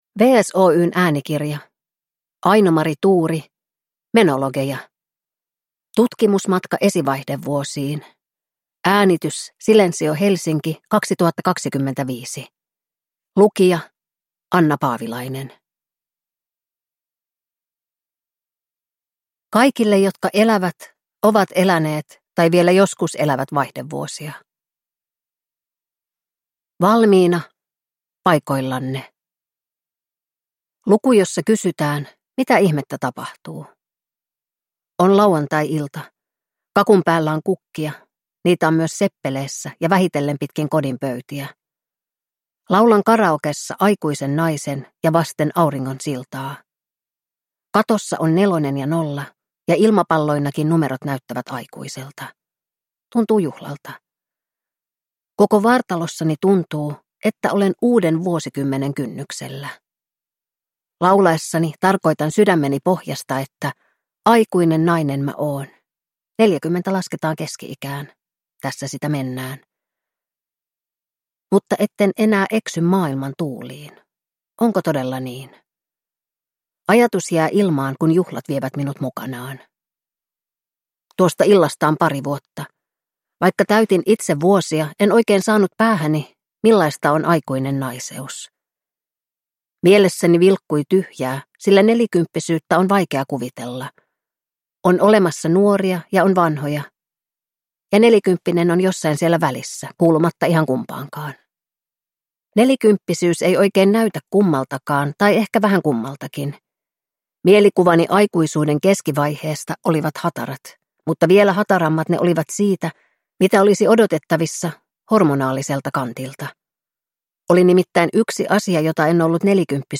Menologeja - Tutkimusmatka esivaihdevuosiin – Ljudbok